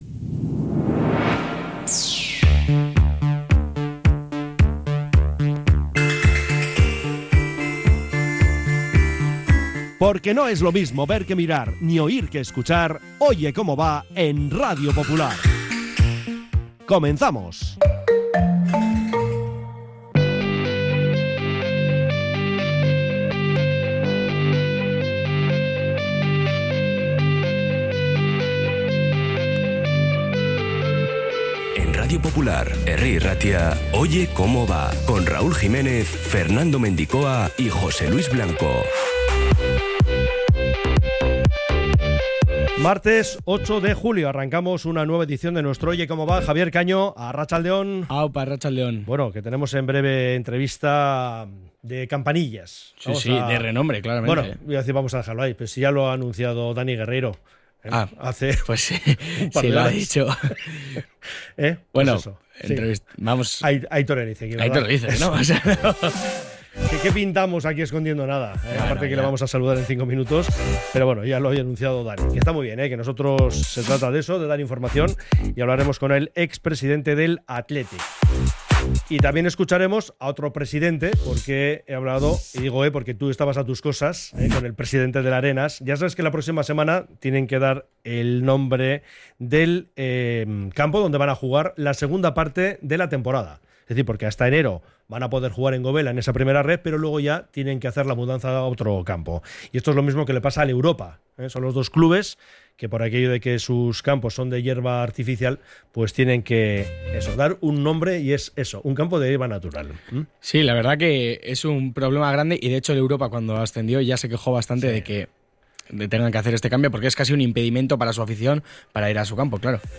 Oye Cómo Va 08-07-25 | Entrevistas